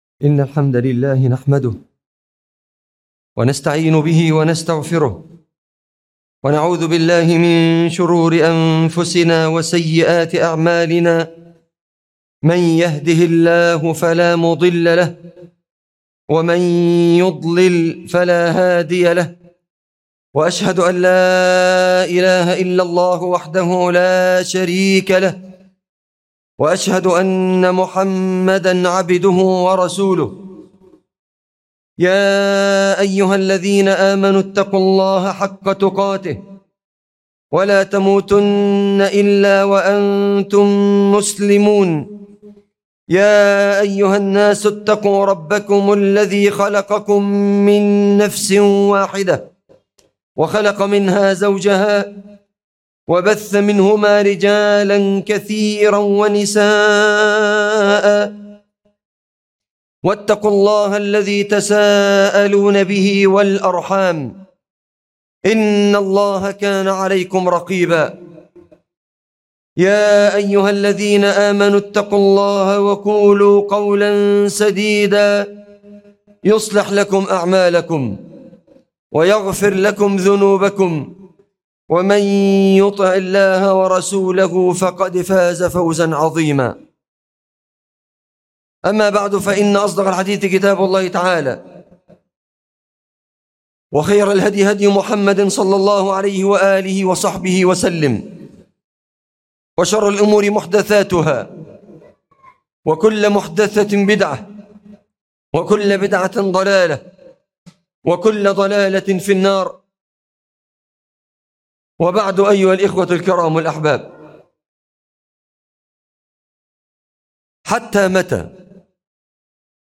حتى متى - خطبة الجمعة